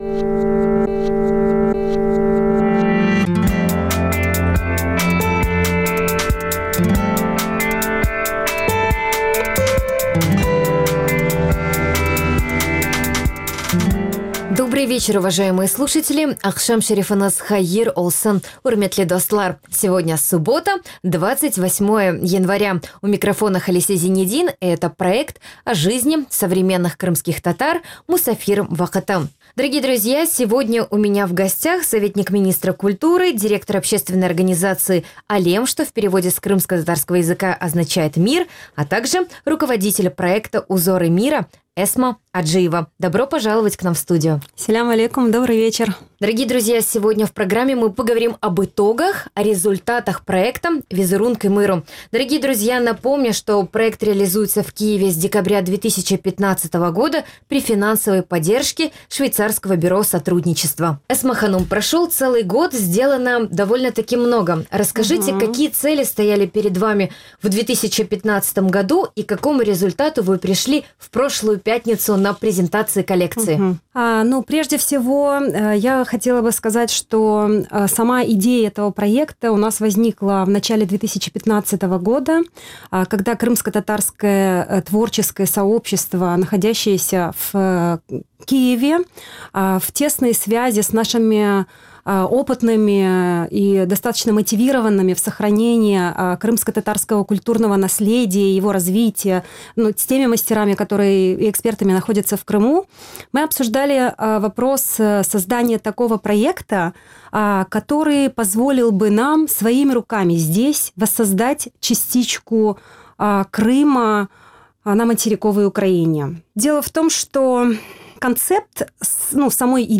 Эфир можно слушать на сайте Крым.Реалии, а также на средних волнах на частоте 549 килогерц.